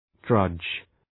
Shkrimi fonetik {drʌdʒ}